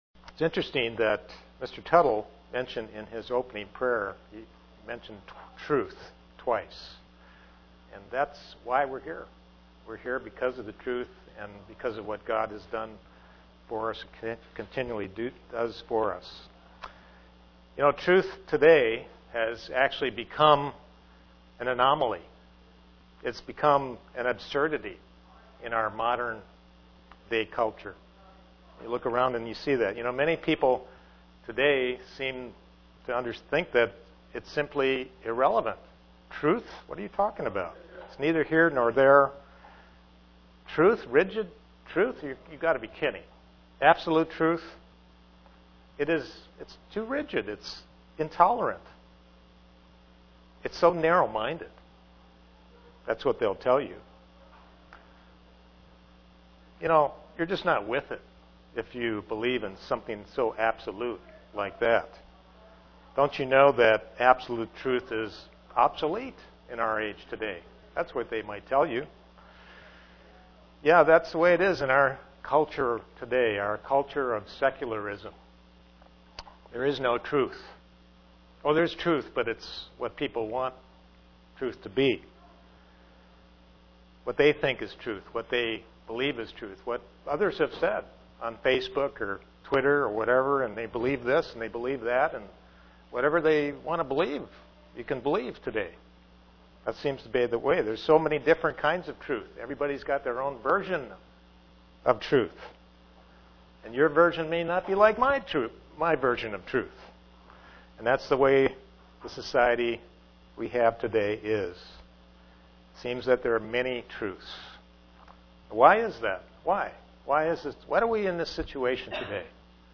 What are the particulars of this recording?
God desires a Christian to be much more than appearance but to live the word throughout their lives. (Presented to the Knoxville TN, Church)